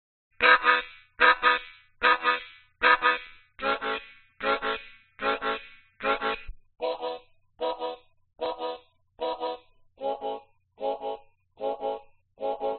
这首歌是A调。 它是A调的，你会发现有特效和无特效的茎。
标签： 器乐 原声 民间 世界 口琴 四弦琴 竖琴 摇篮曲 新年
声道立体声